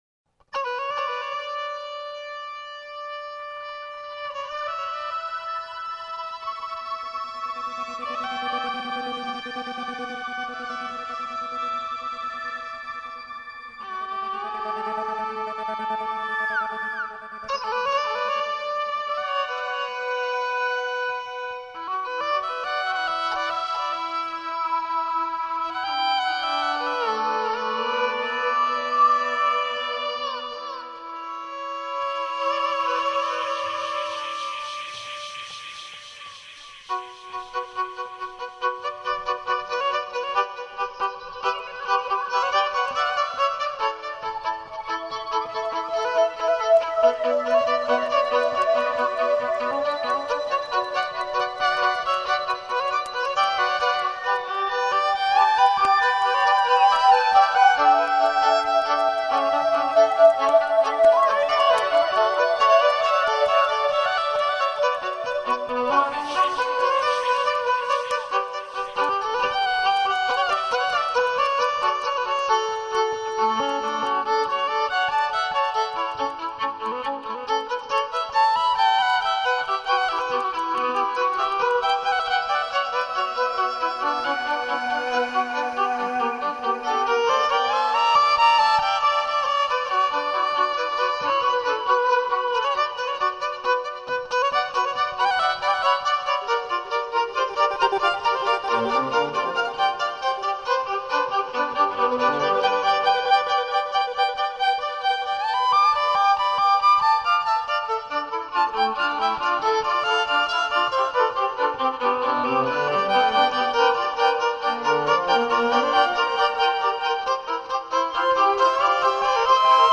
Fusion / Compilation